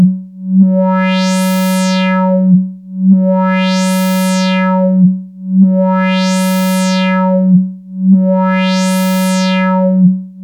vcf lfo tri mod high res.mp3